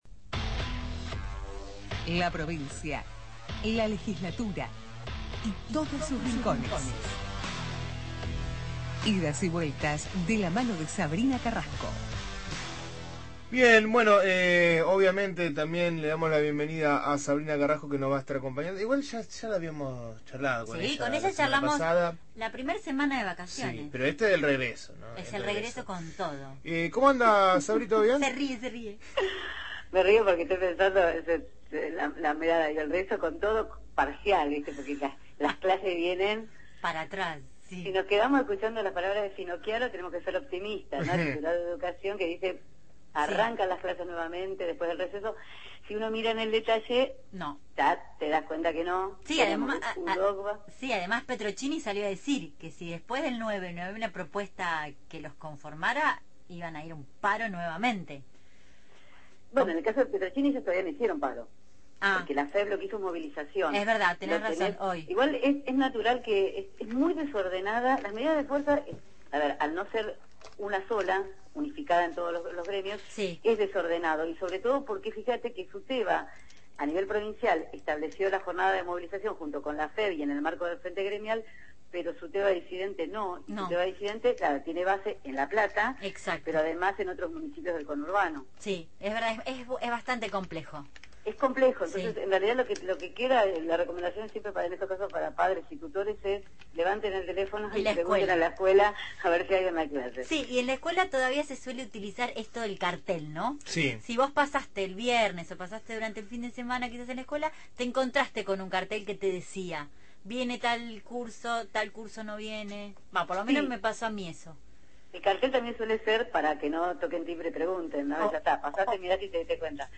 Columna de política provincial